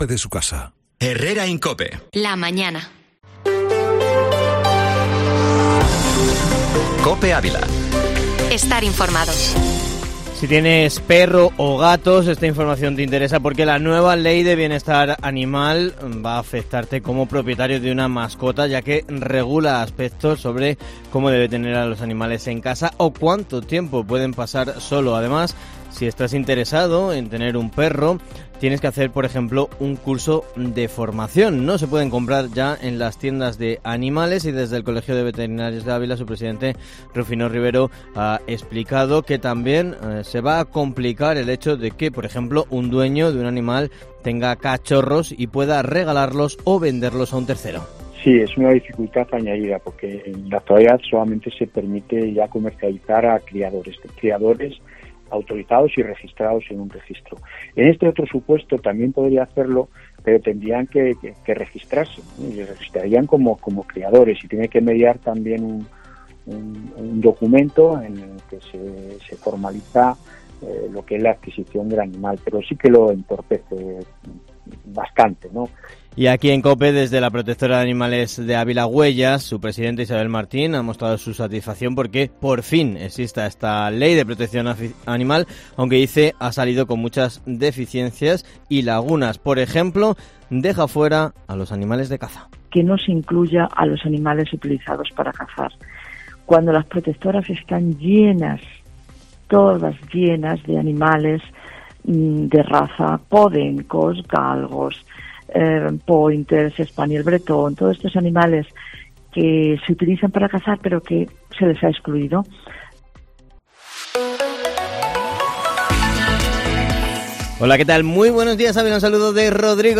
Informativo Matinal Herrera en COPE Ávila, información local y provincial